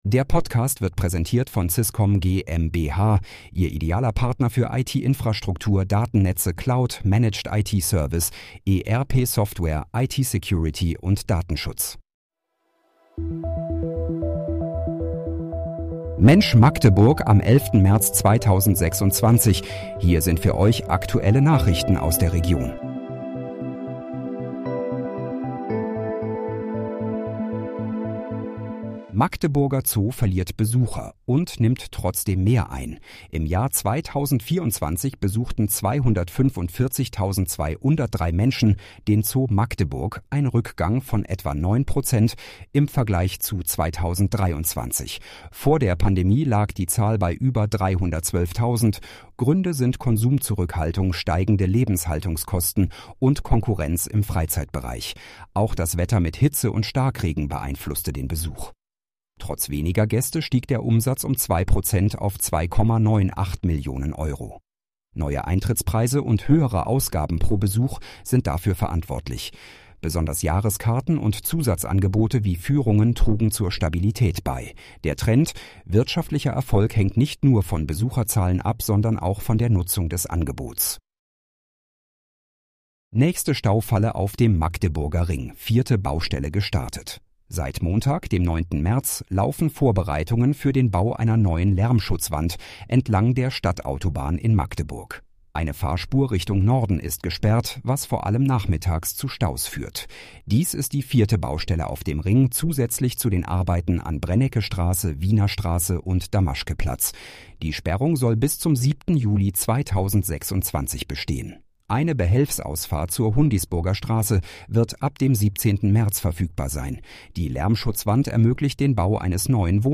Mensch, Magdeburg: Aktuelle Nachrichten vom 11.03.2026, erstellt mit KI-Unterstützung